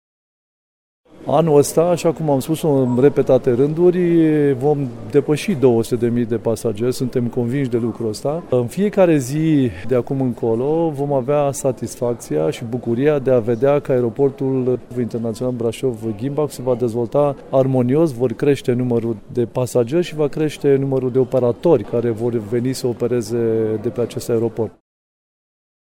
Cu acest prilej, președintele Consiliului Județean Brașov, Șerban Todorică, a anunțat că anul acesta, pe aeroportul de la Ghimbav vor opera mai multe companii aeriene, astfel că sunt toate șansele ca numărul pasagerilor să se dubleze în scurt timp: